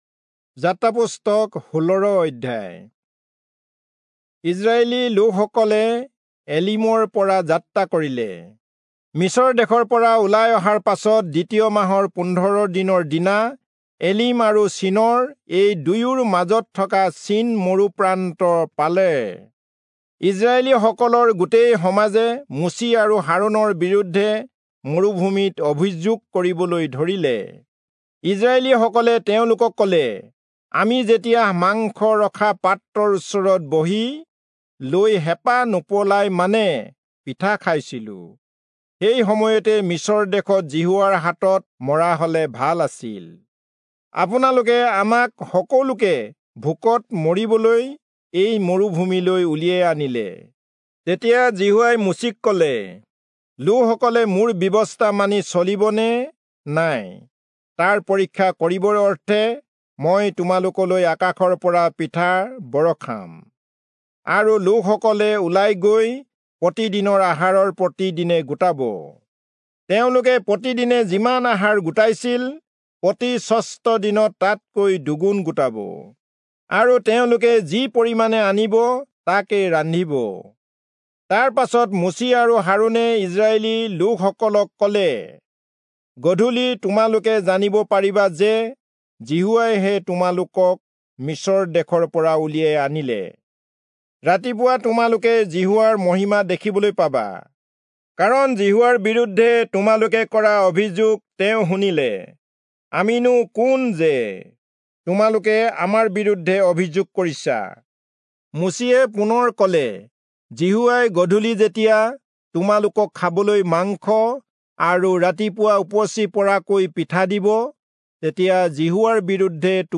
Assamese Audio Bible - Exodus 19 in Rv bible version